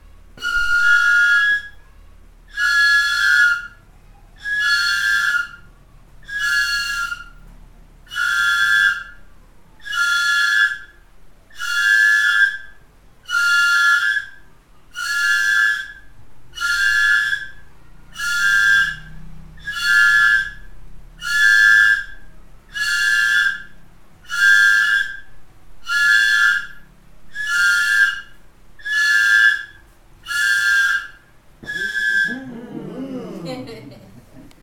Leticia, Amazonas, (Colombia)
Grupo de Danza Kaɨ Komuiya Uai
Toque de las flautas zikango. Se tocan en pares.
Playing of the zikango flutes. They are played in pairs.